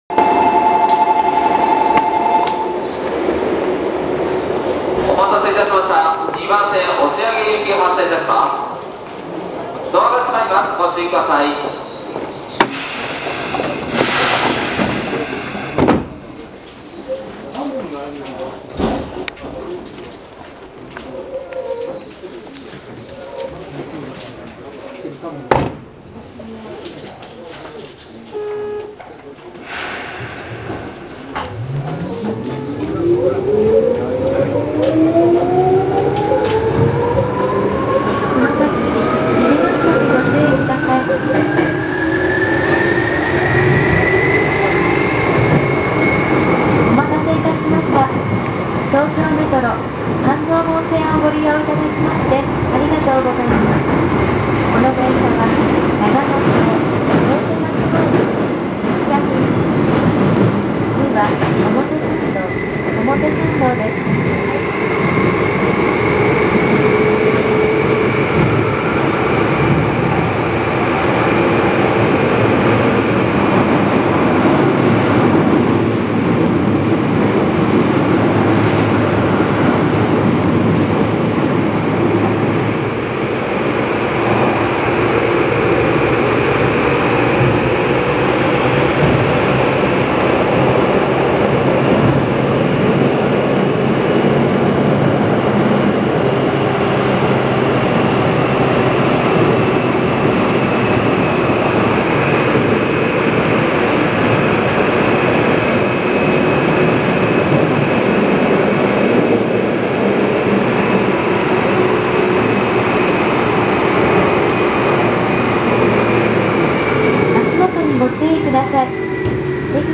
・8590系走行音
【東京メトロ半蔵門線】渋谷→表参道（2分20秒：762KB）
8000系統の一員であるので、田園都市線にも大井町線にもたくさん在籍している8500系と走行音は全く同じですが、何故か東急と東京メトロの自動放送に対応しています。とはいえ、走行音が大きいので地下区間ではイマイチよく聞こえないのですが。